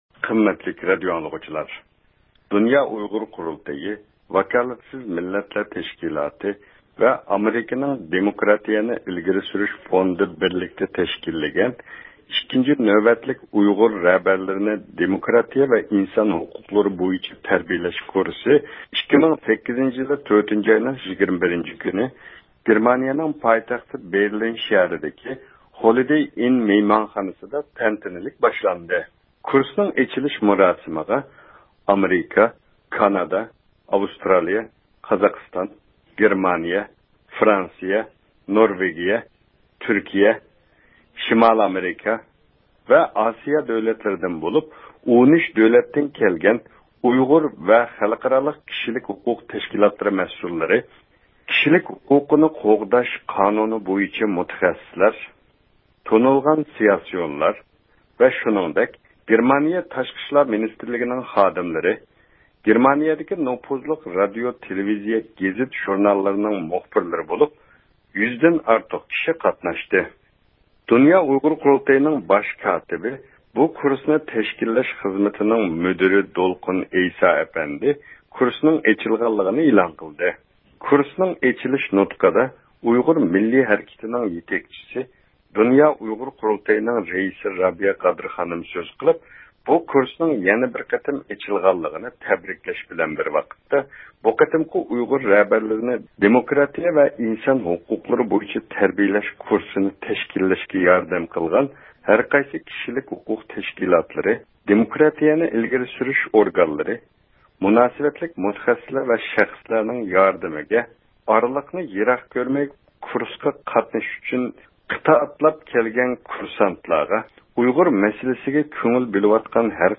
ﺧﻪﯞﯨﺮﻯ